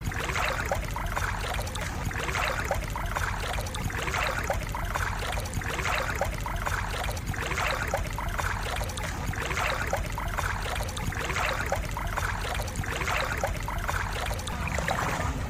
Tiếng Lội nước, Đi trong nước… sột soạt
Thể loại: Tiếng động
Description: Tiếng lội nước, tiếng bước chân trong nước sột soạt, bì bõm, vỡ bọt, vỗ nước và xào xạc tạo nên hiệu ứng âm thanh sống động khi edit video.
tieng-loi-nuoc-di-trong-nuoc-sot-soat-www_tiengdong_com.mp3